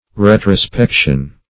Retrospection \Re`tro*spec"tion\, n.